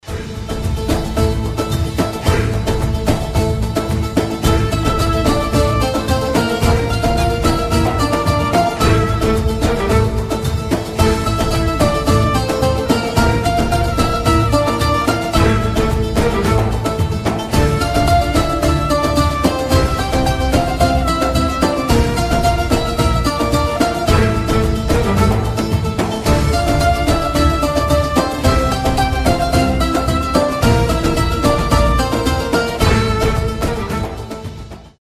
• Качество: 320, Stereo
восточные
турецкие